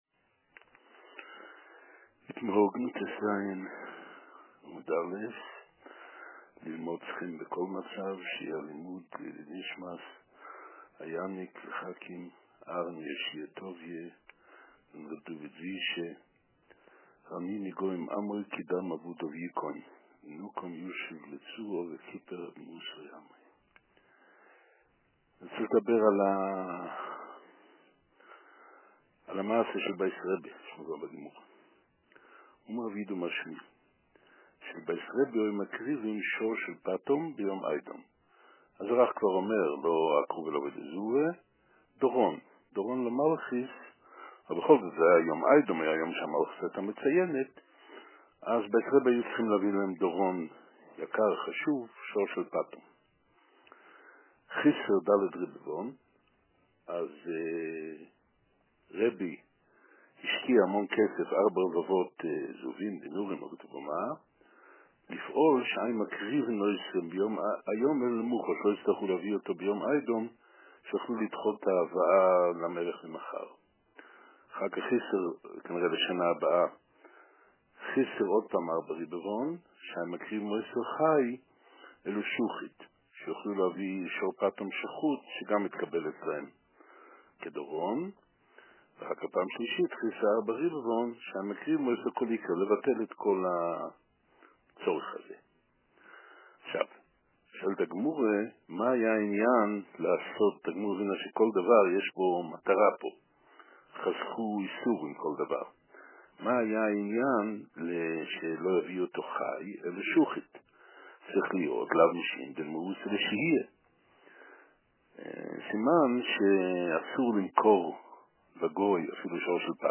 פתח הבוקר את שיעורו בהתייחסות לרצח בן קהילתו